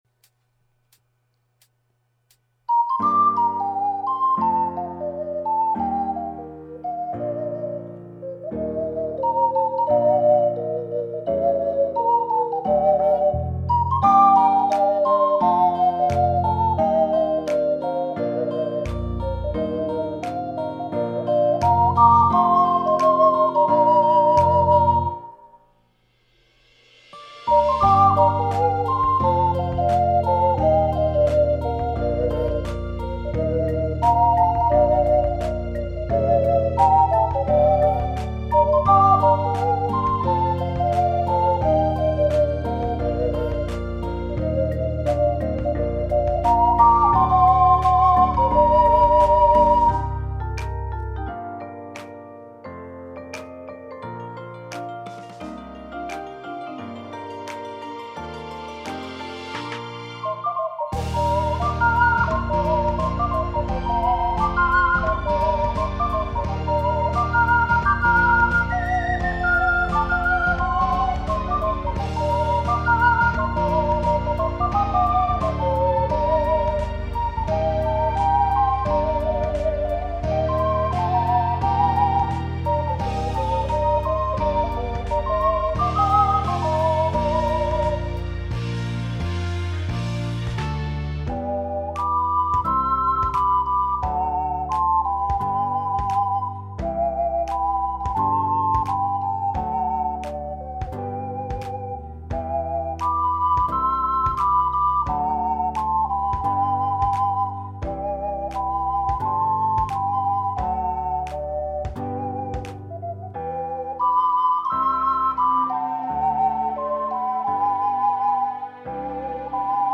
＊試奏は雑に録音・編集したので、参考程度と思ってください。
Duet   ①AC+SF
②AF+AC   2ndの最初はAF管で吹き、間奏は吹かずにAC管に持ち替えました。